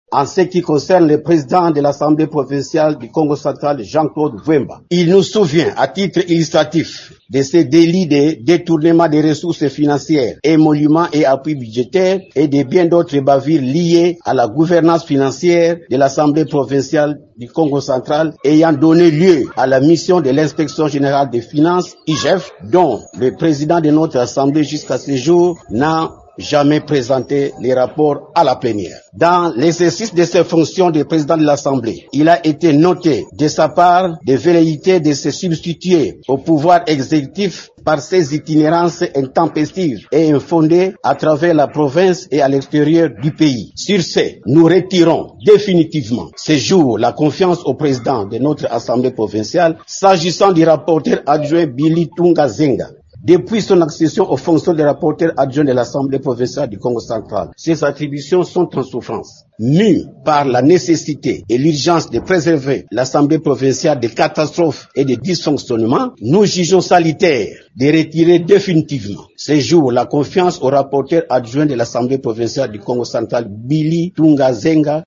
Leur déclaration est lue par le député provincial Pierrot Mvumbi :